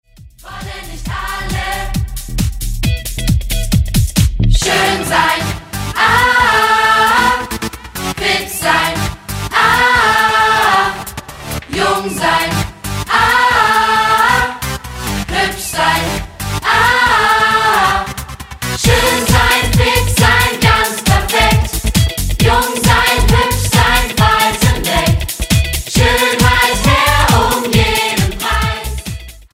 Musical-CD
14 Lieder und kurze Theaterszenen